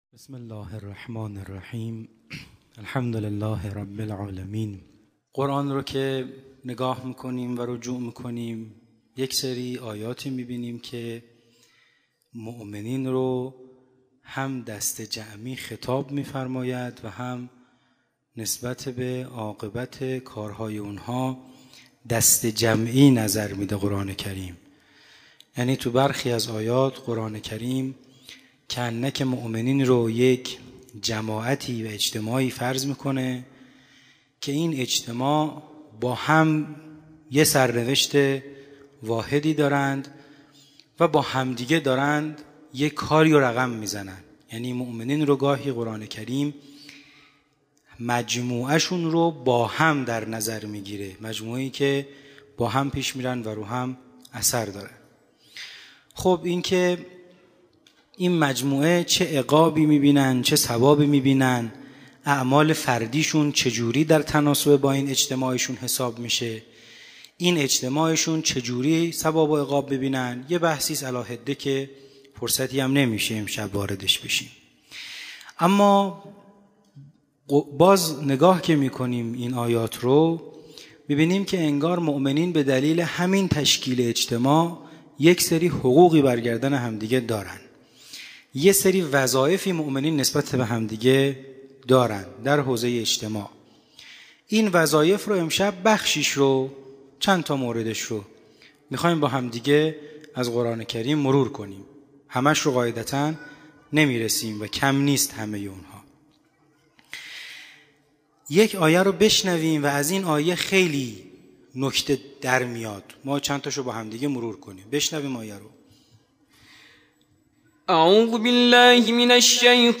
همچنین آیات استناد شده در خلال سخنرانی، توسط یکی از قاریان ممتاز دانشگاه به صورت ترتیل قرائت می‌شود.